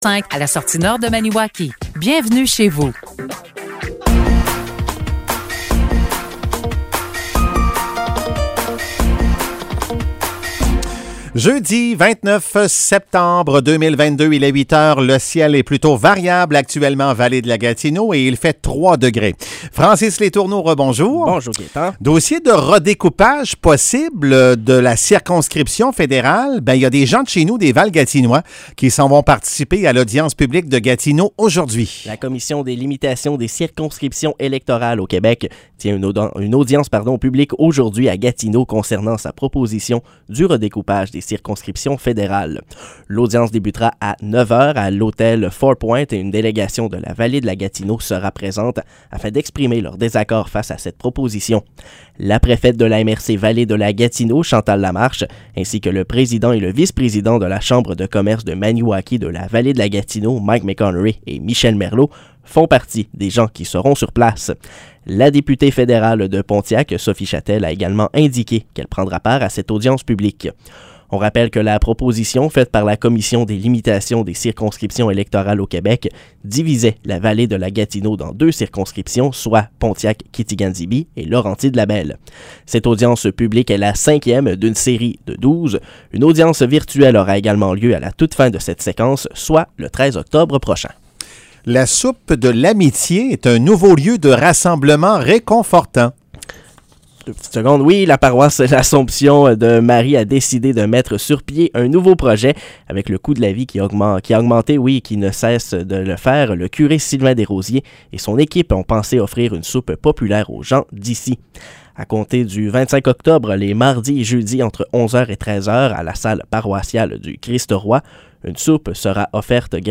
Nouvelles locales - 29 septembre 2022 - 8 h